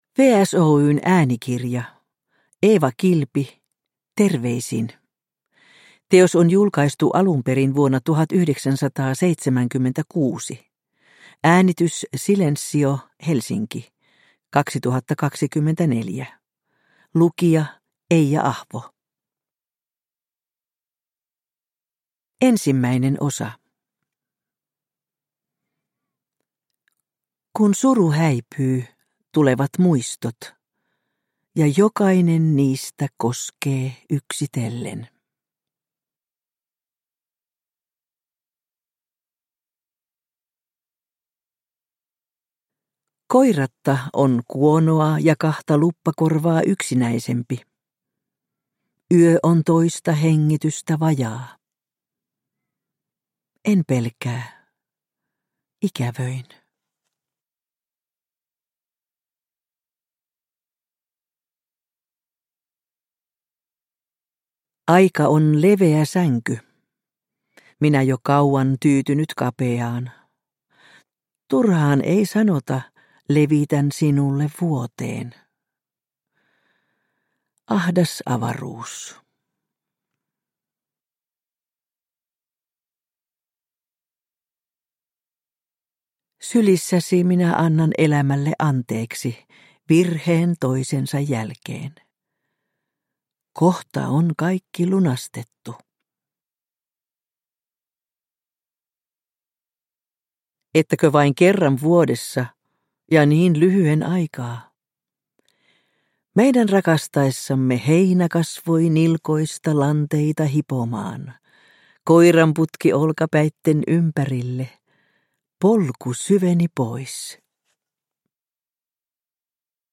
Terveisin – Ljudbok